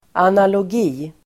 Ladda ner uttalet
Uttal: [analåg'i:]